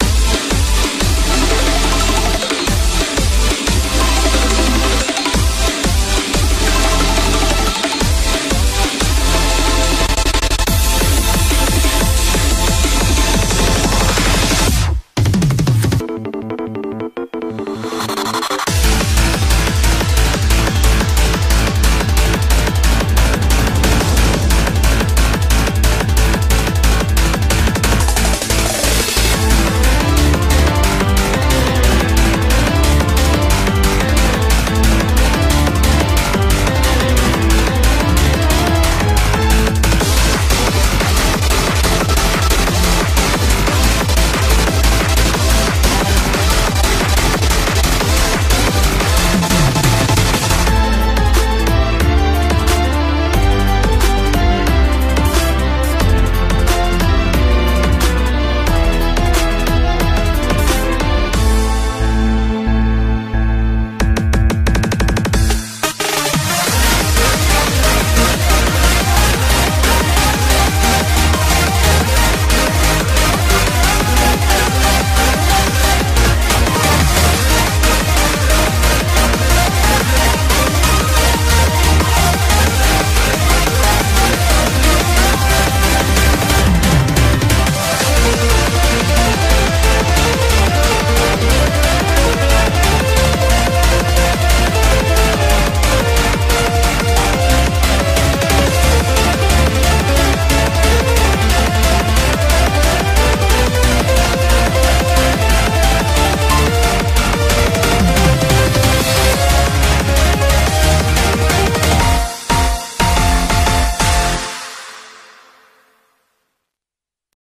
BPM180
Audio QualityCut From Video